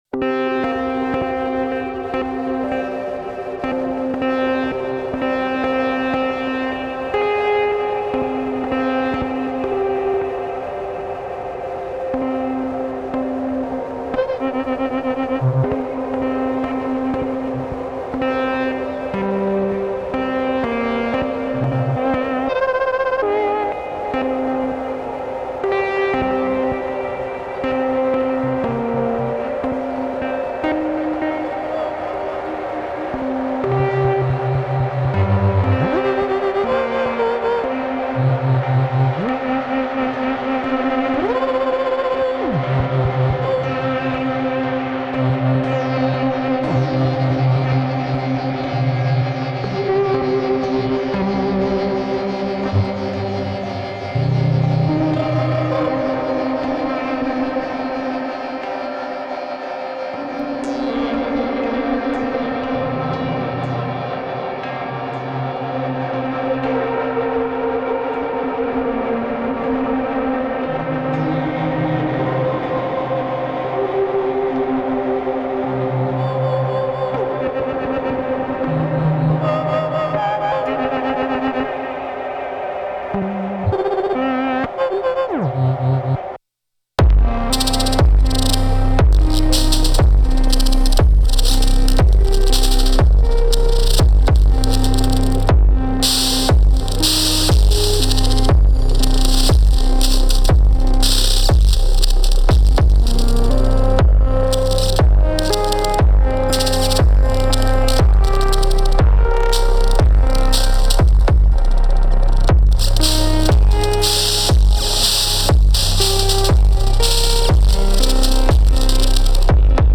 Those Hats from the Rytm’s Noise Machine are just so sick.